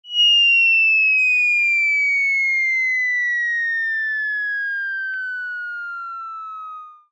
cogbldg_drop.ogg